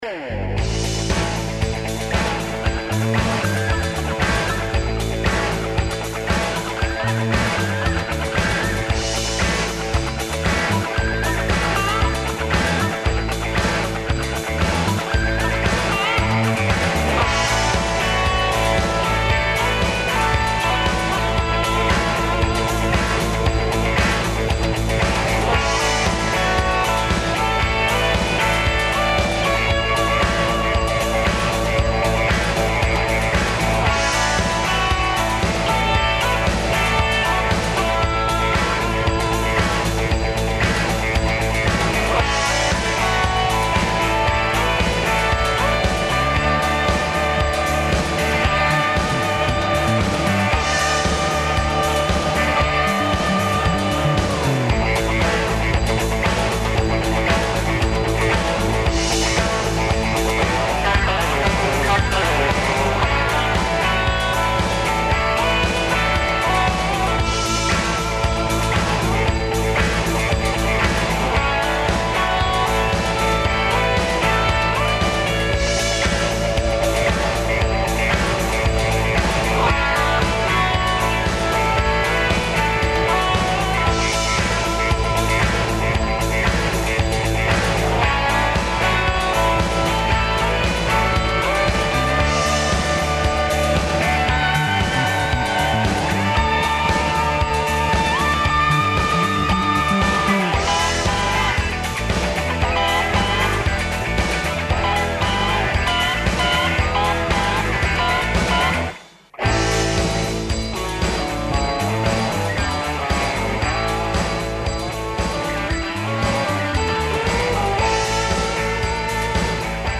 О томе како без муке до витке линије, и како се здраво хранити чак и ако имате врло мало слободног времена разговараћемо са нутриционистима-дијетолозима